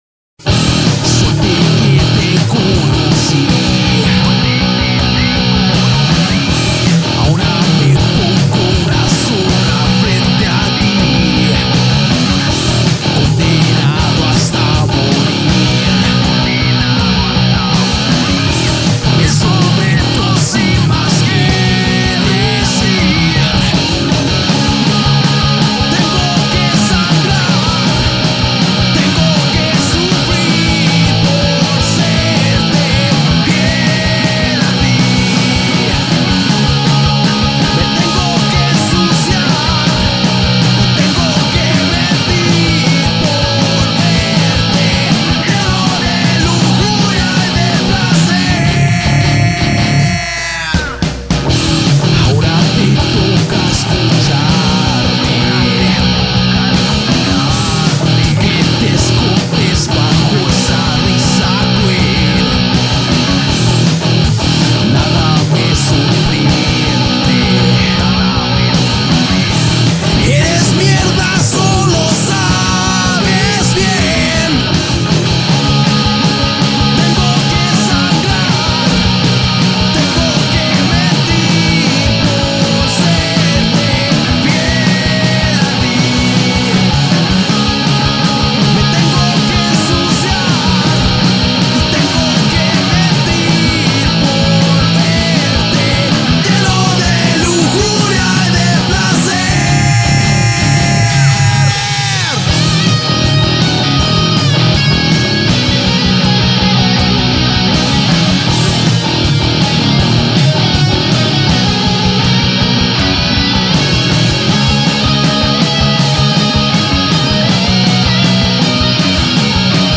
Post-grunge